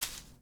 SFX_paso5.wav